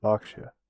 En-Berkshire.ogg.mp3